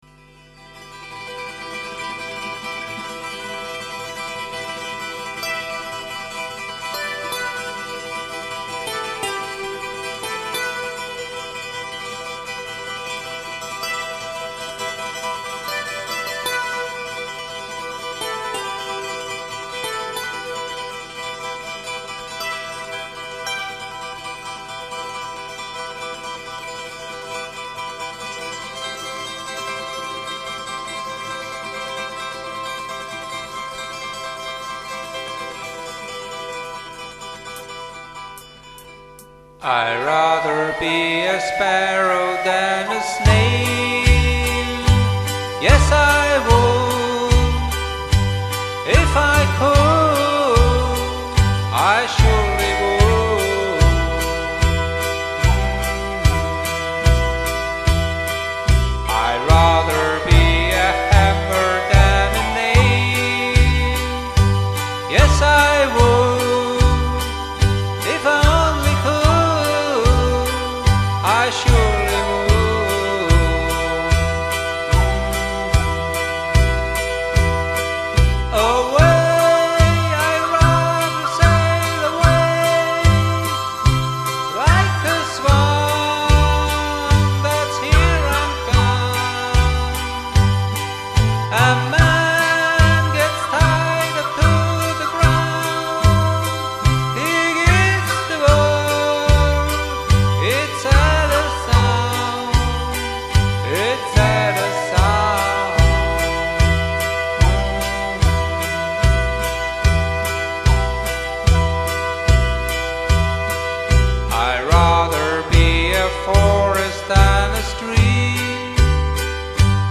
Karaoke MP3 Version